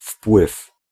Ääntäminen
Ääntäminen Tuntematon aksentti: IPA: /fpwɨf/ Haettu sana löytyi näillä lähdekielillä: puola Käännös Ääninäyte 1. influencia {f} 2. consecuencia {f} 3. impacto {m} 4. efecto {m} Suku: m .